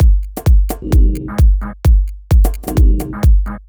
Zzaj 130bpm.wav